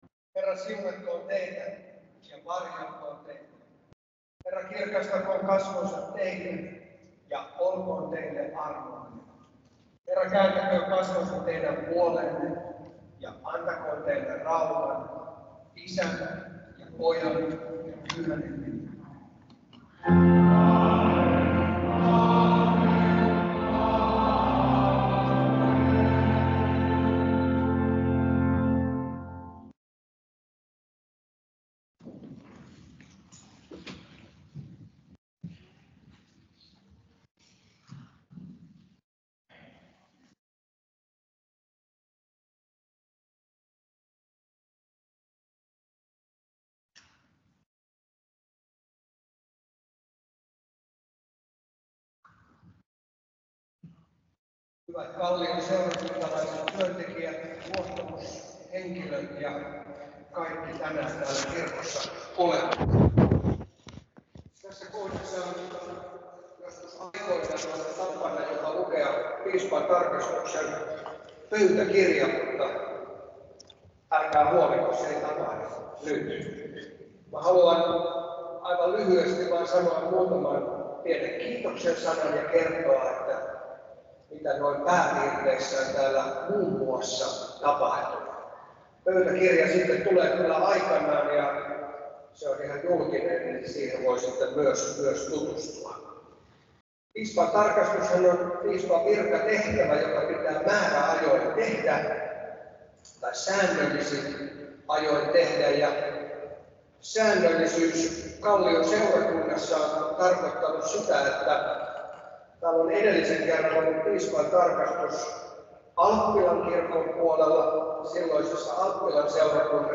2024年3月24日 Teemu Laajasalo 主教视察工作讲话